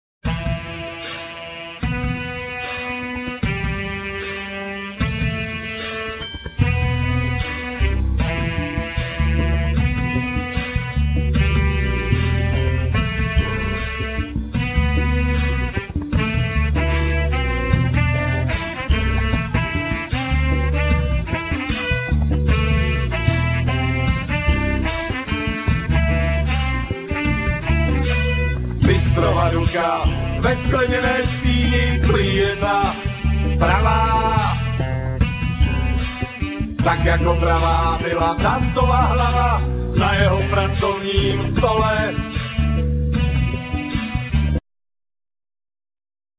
Vocals,Saxes,Guitars,Keyboards,Sirens
Drums,Percussions,Groove box,Electronics
Bass,Didgeridoo